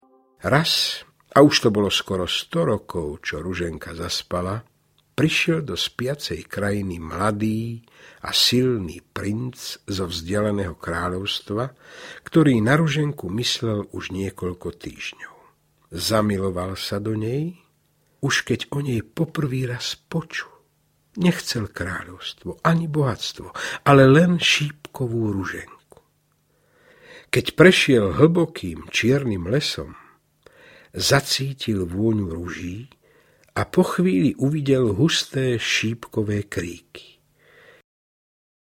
Najkrajšie rozprávky 6 audiokniha
Obsahuje rozprávky Šípková Ruženka, Sindibád námorník a Sultánov šašo, v podaní výborného Mariána Labudu.
Ukázka z knihy